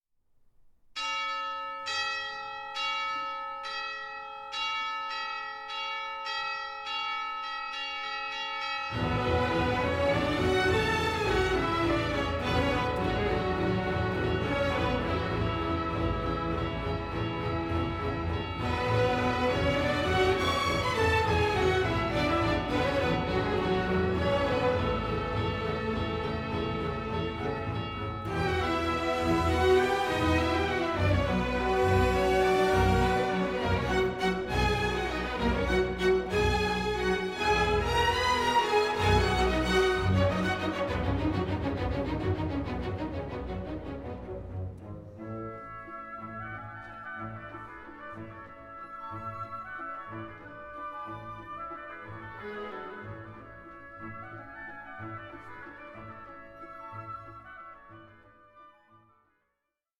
Chorus
A SYMPHONIC APPROACH TO VERISMO